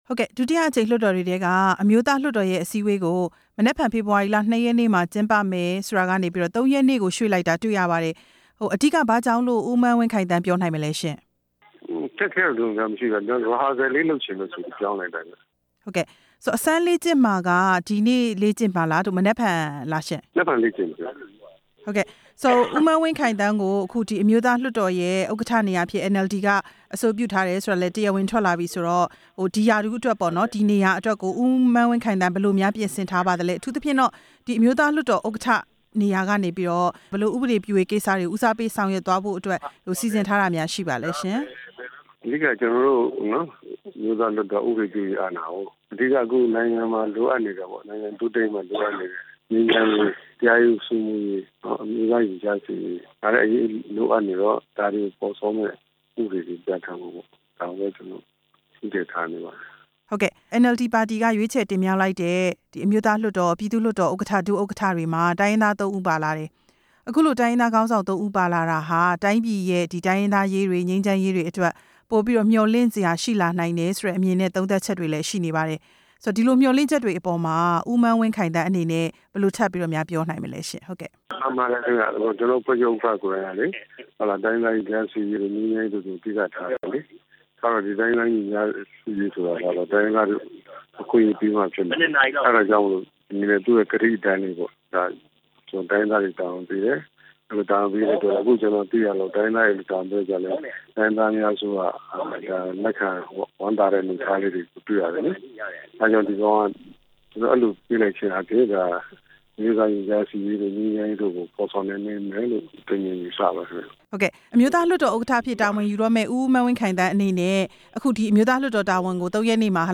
မန်းဝင်းခိုင်သန်း ကို ဆက်သွယ်မေးမြန်းချက်